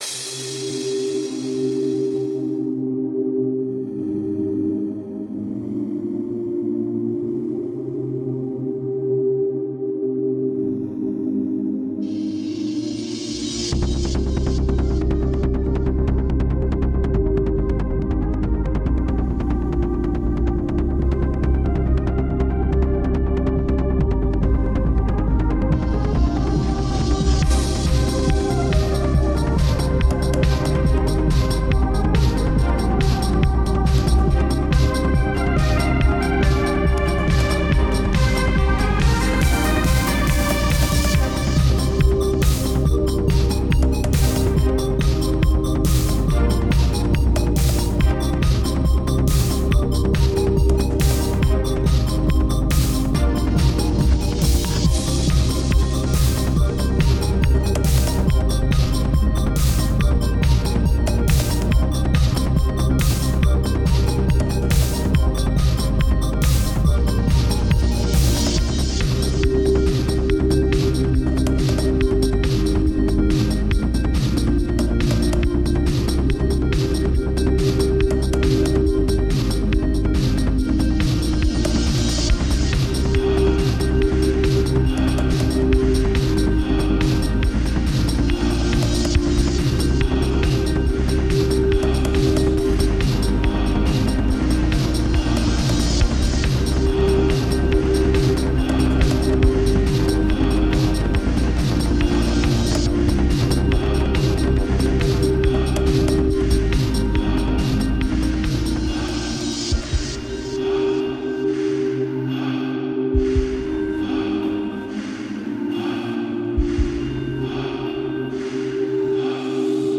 A new mix of tracks